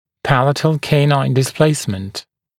[‘pælətl ‘keɪnaɪn dɪs’pleɪsmənt] [‘пэлэтл ‘кейнайн дис’плэйсмэнт] небная дистопия клыка (клыков)